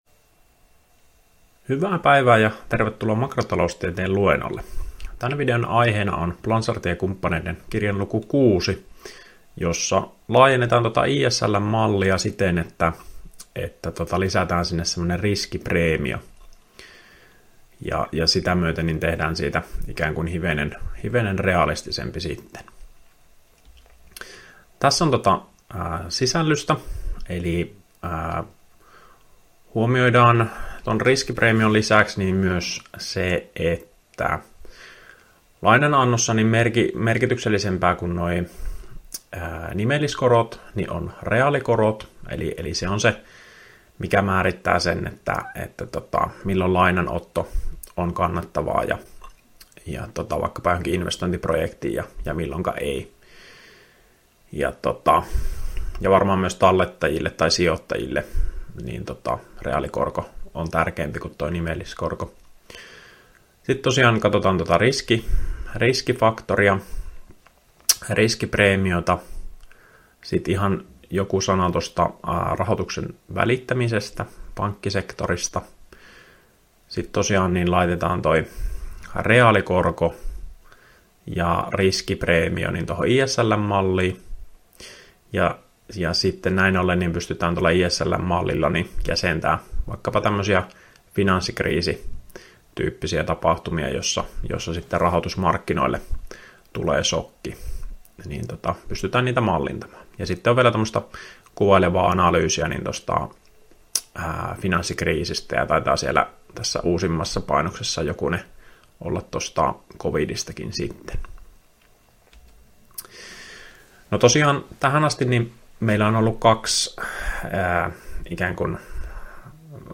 Opintojakson "Makrotaloustiede I" suhdanneosion 5. opetusvideo.